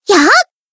BA_V_Aris_Maid_Battle_Shout_1.ogg